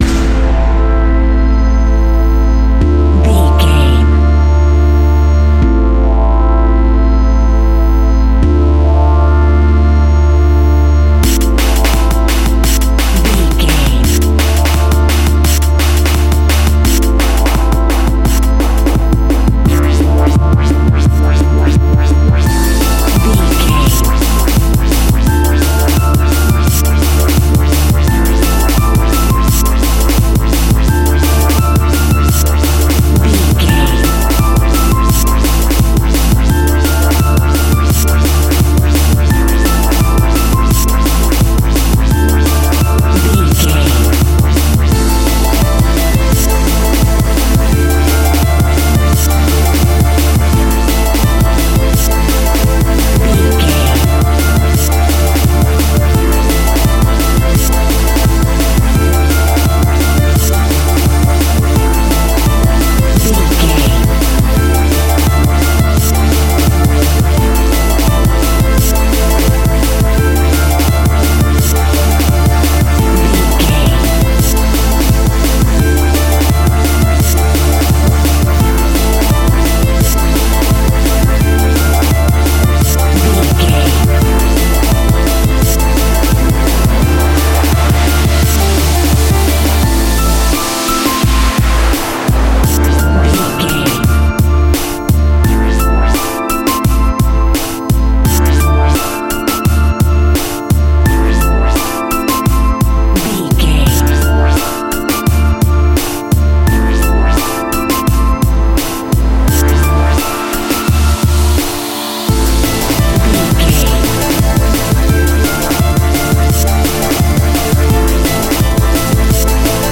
Aeolian/Minor
Fast
groovy
dark
futuristic
funky
energetic
frantic
driving
synthesiser
drums
drum machine
Drum and bass
electronic
instrumentals
synth bass
synth lead
synth pad
robotic